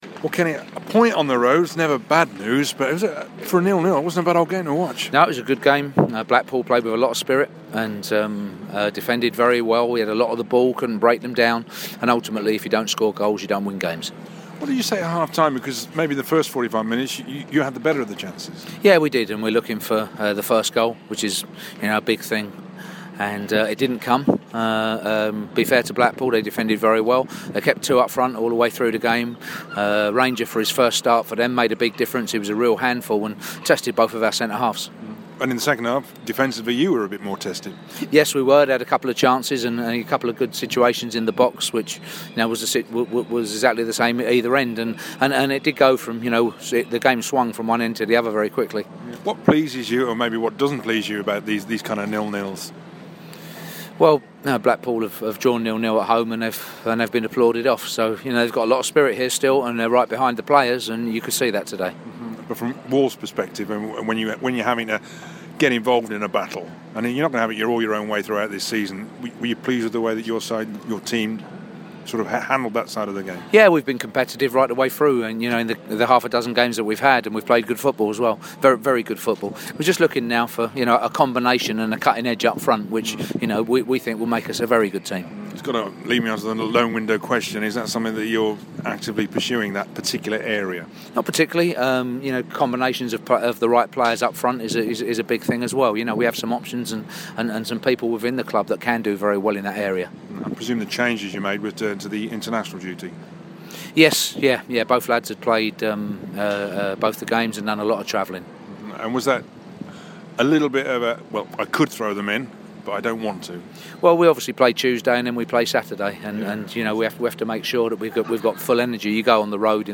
speaks to Kenny Jackett post-match.